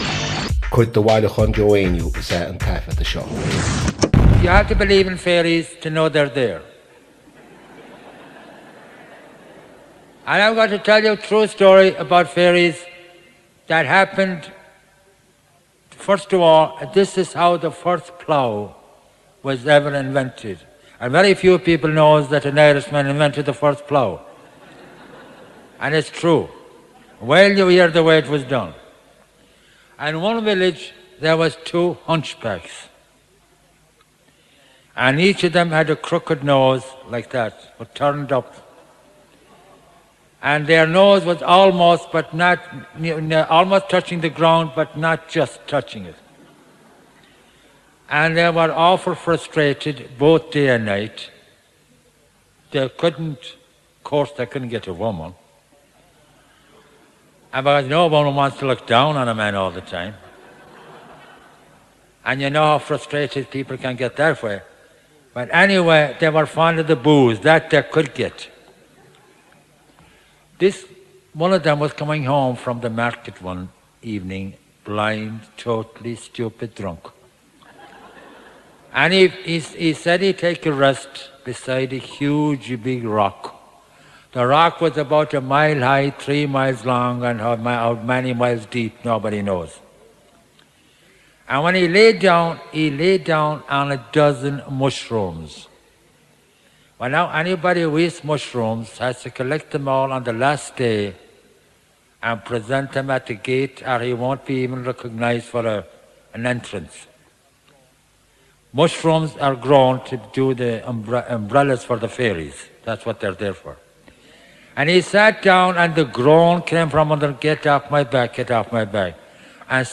• Catagóir (Category): story.
• Ainm an té a thug (Name of Informant): Joe Heaney.
• Suíomh an taifeadta (Recording Location): Sydney Opera House, Australia.
• Ocáid an taifeadta (Recording Occasion): concert.